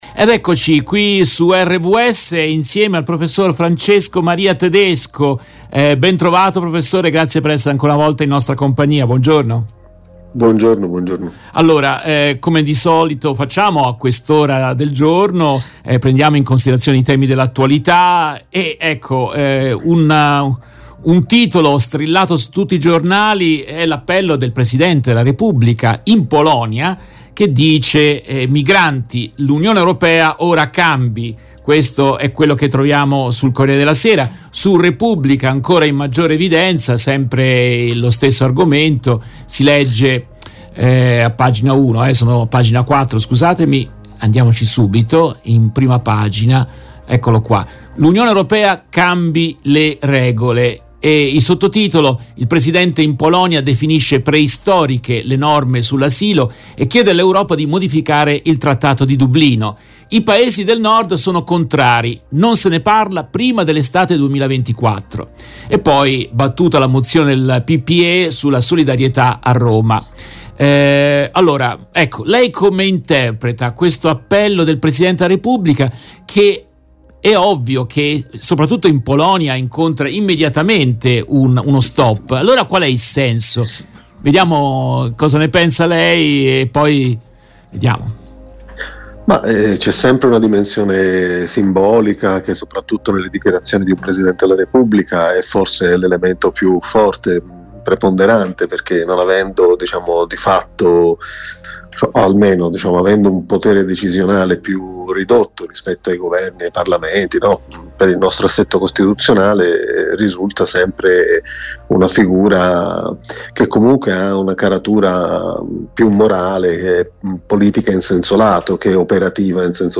Nell’intervista estratta dalla diretta del 18 aprile 2023